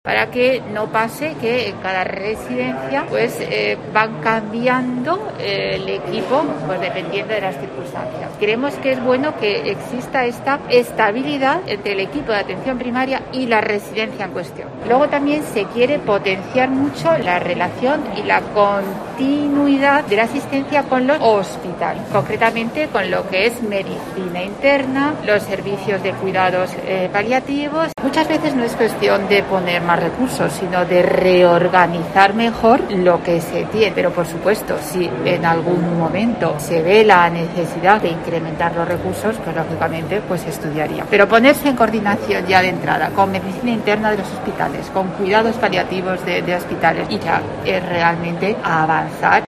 Escucha a la delegada de Salud y Familias de la Junta en Córdoba, María Jesús Botella